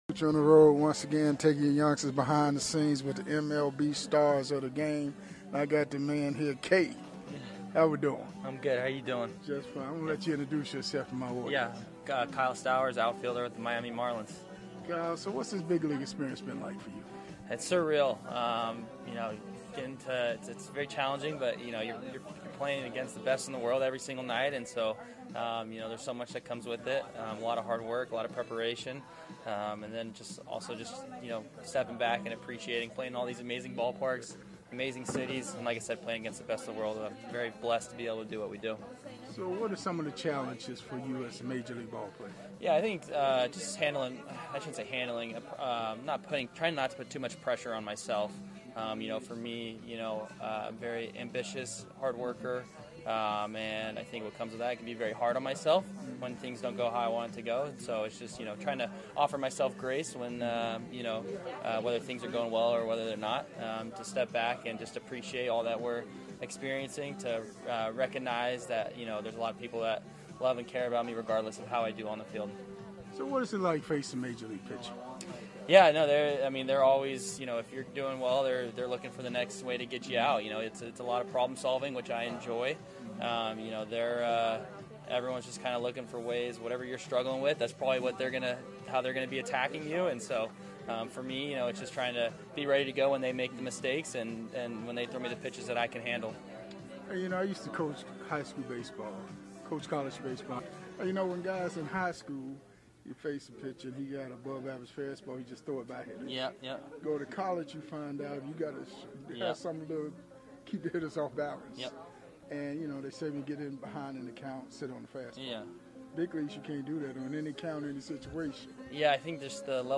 Classic MLB Interviews with the stars of the games